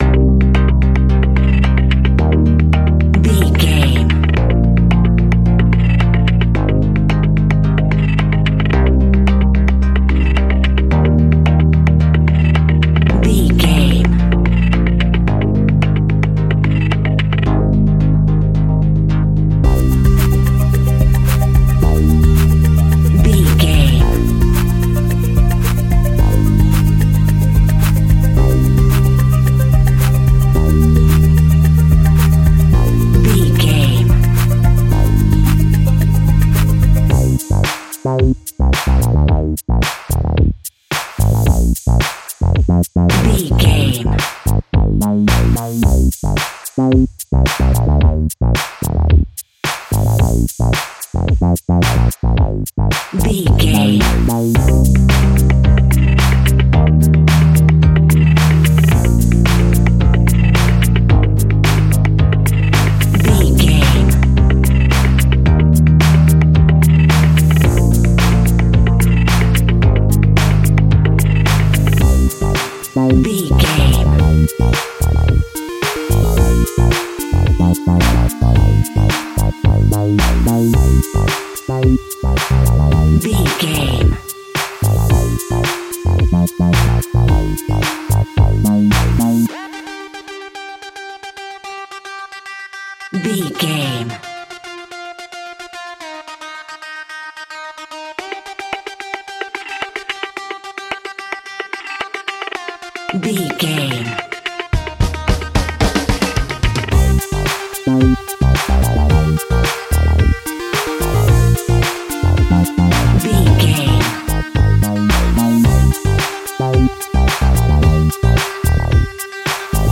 Groove Chilling Funk.
Aeolian/Minor
groovy
drums
bass guitar
synthesiser
electronic
chillwave
funktronica
synth leads
synth bass